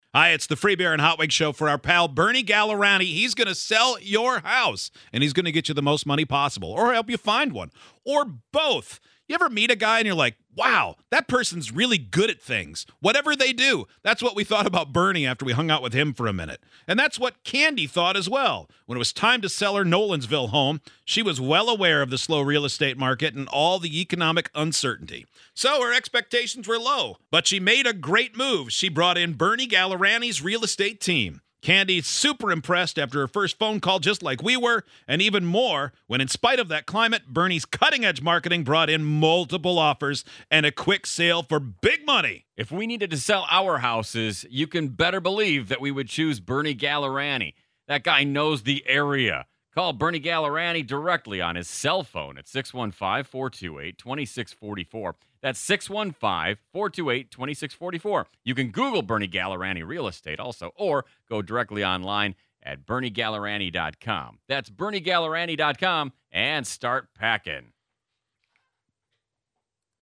Celebrity Real Estate Agent Endorsements (Celebrity Cameos for Realtors) Celebrity Endorsement Agency | Radio & Television Experts